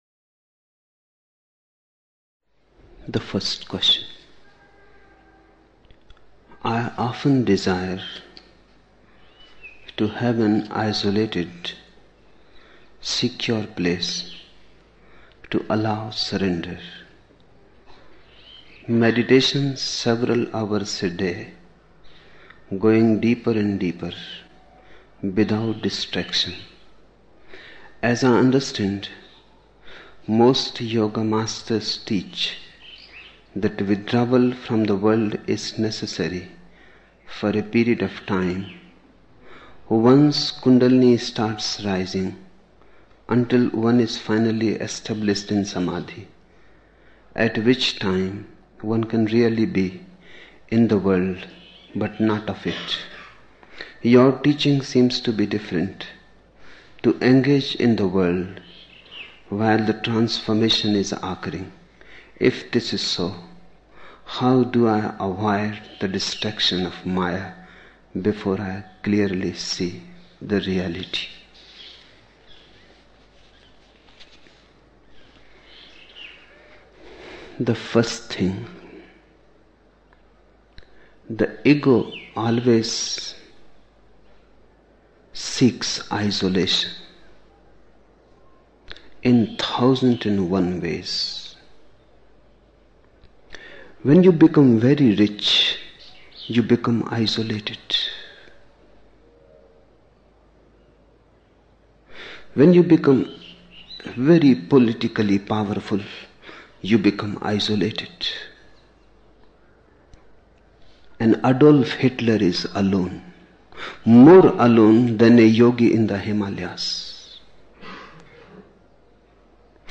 18 December 1975 morning in Buddha Hall, Poona, India